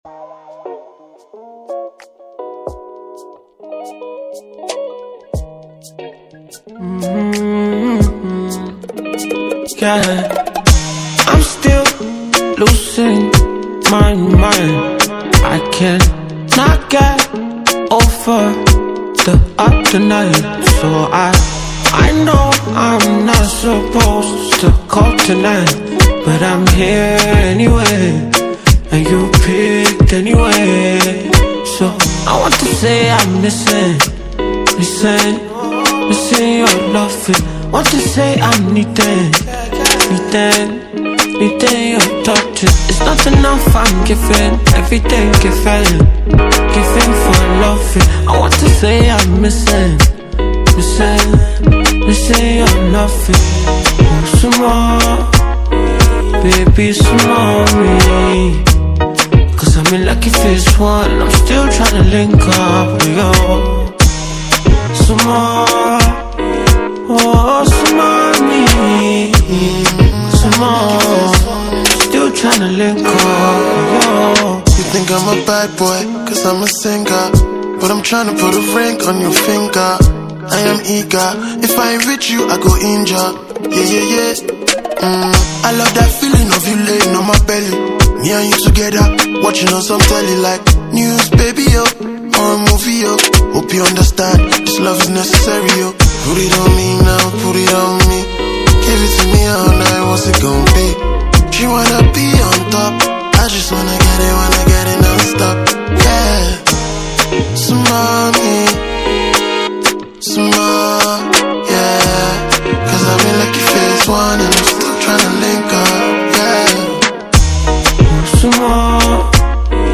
Nigerian Alte singer